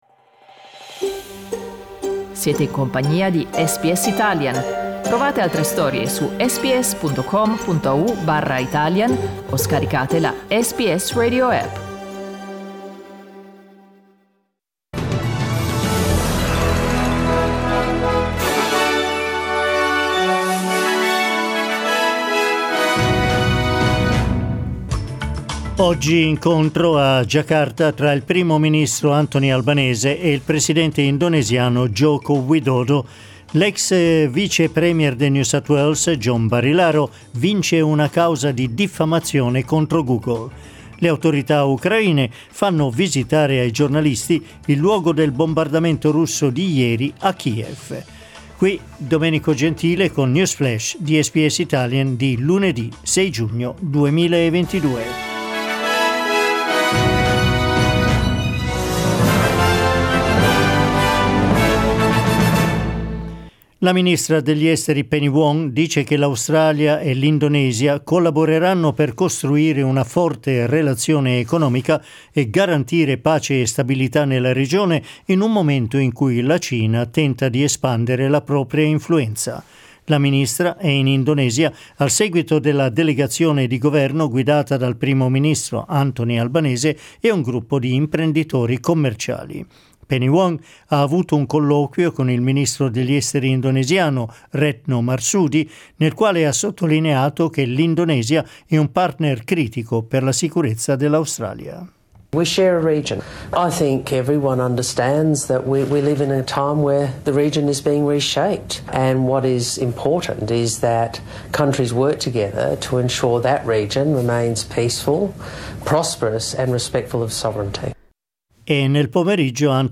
News flash lunedì 6 giugno 2022
L'aggiornamento delle notizie di SBS Italian.